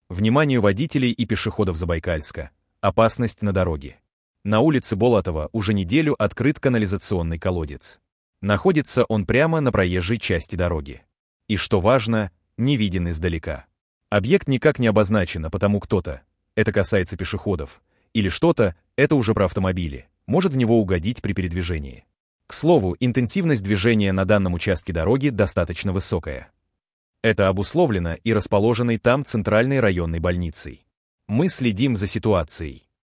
Аудиовариант новости !